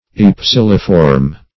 Search Result for " ypsiliform" : The Collaborative International Dictionary of English v.0.48: Ypsiliform \Yp*sil"i*form\, a. [Gr.